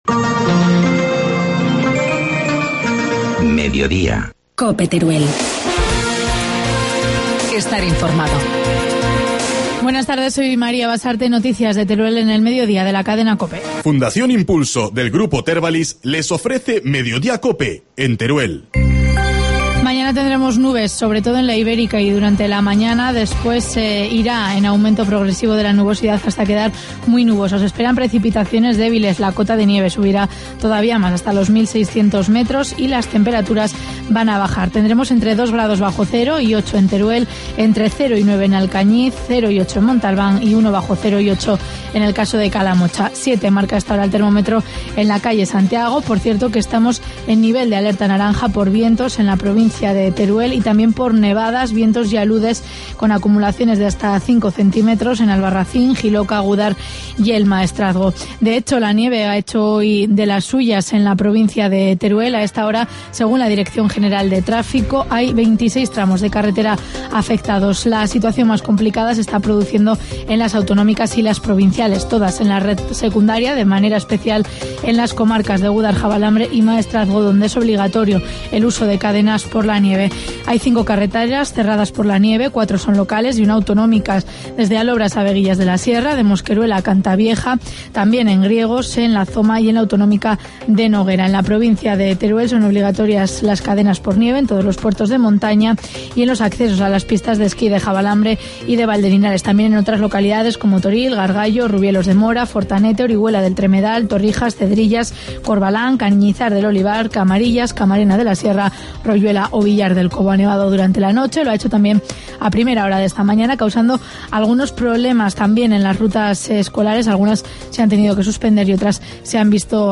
Informativo mediodía, jueves 24 de enero